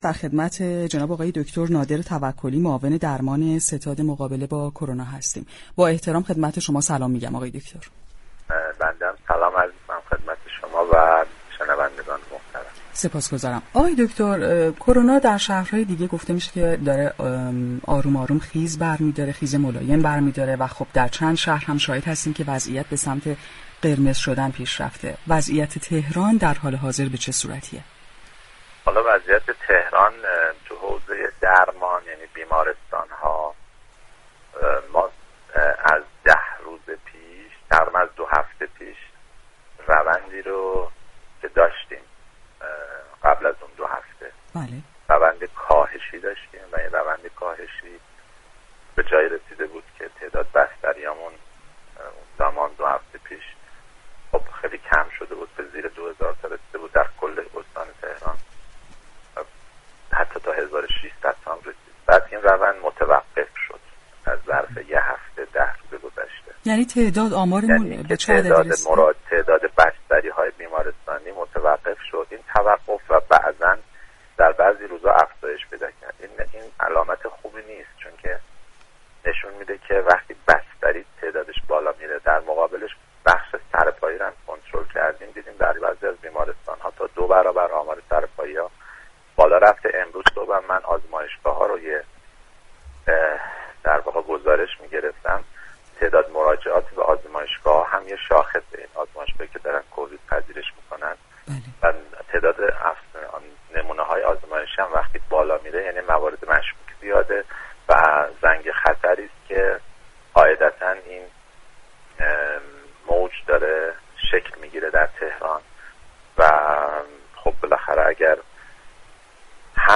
نادر توكلی در گفت‌وگو با برنامه تهران ما سلامت رادیو تهران درباره خیز بیماری كرونا در شهر تهران طی هفته اخیر اظهار داشت: پیش از دو هفته پیش تعداد بستری ها كاهش پیدا كرد و حتی به زیر 1600 بستری رسید.